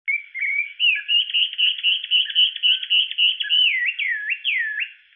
13-2柴山畫眉may10-2.mp3
臺灣畫眉 Garrulax taewanus
高雄市 鼓山區 柴山
錄音環境 雜木林
行為描述 鳴唱
收音: 廠牌 Sennheiser 型號 ME 67